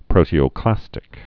(prōtē-ō-klăstĭk)